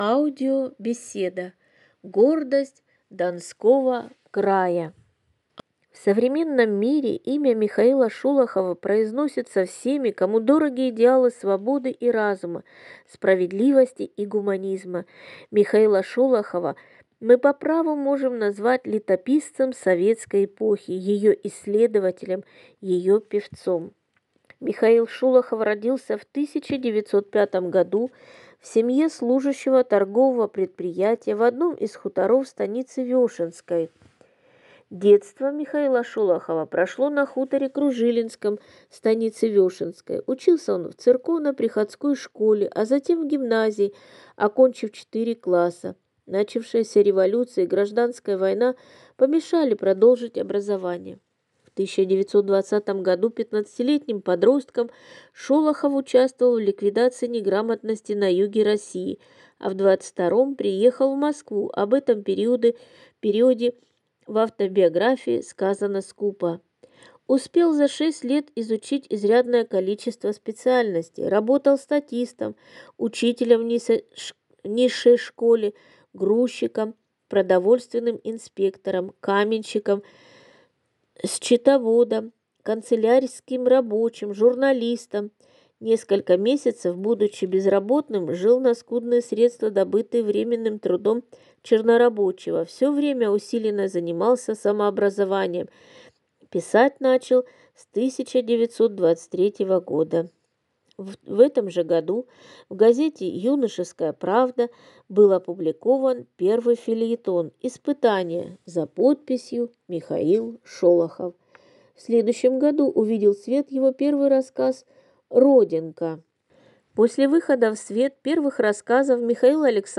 аудиобеседе